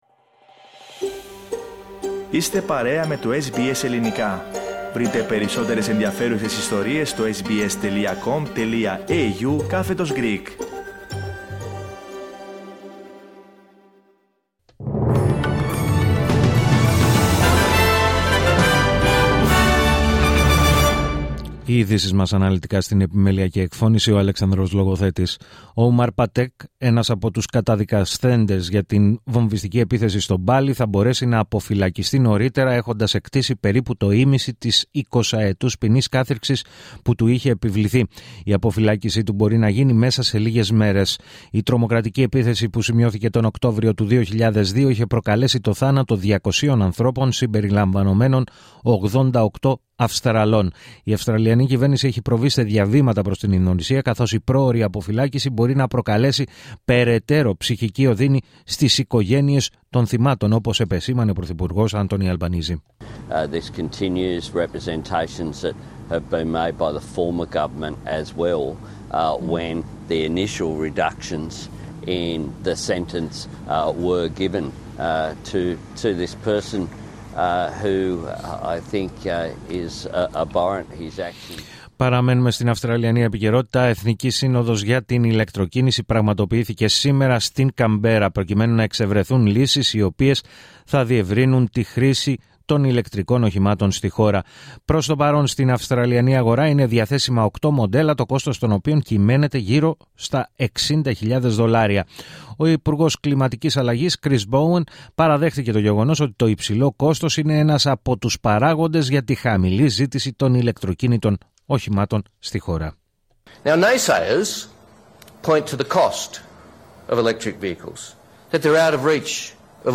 Το αναλυτικό δελτίο ειδήσεων του Ελληνικού Προγράμματος της ραδιοφωνίας SBS, στις 4 μμ.